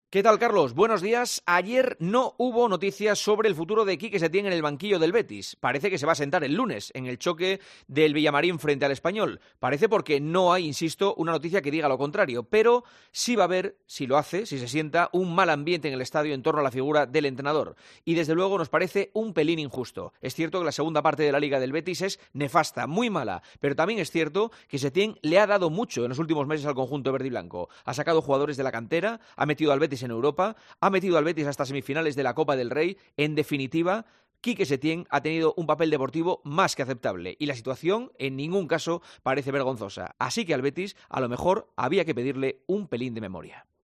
Escucha el comentario de Juanma Castaño en 'Herrera en COPE''